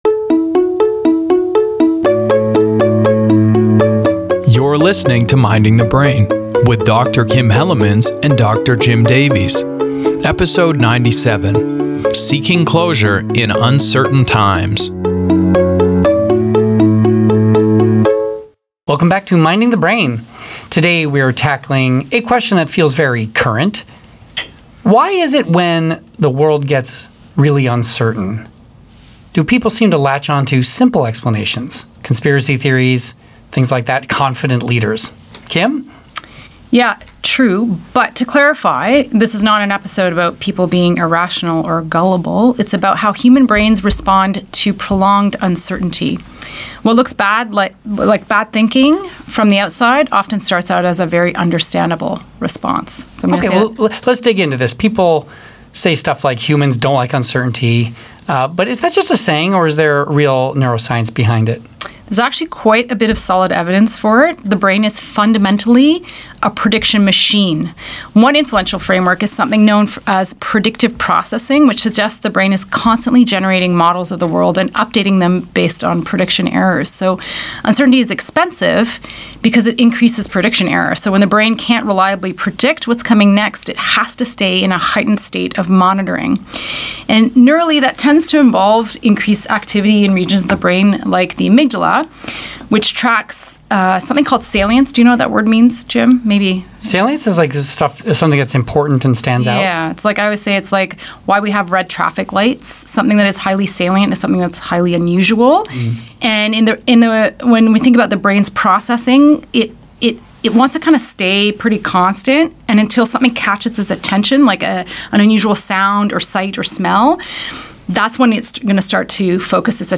a timely conversation that touches on social media, authoritarianism, nuance and more.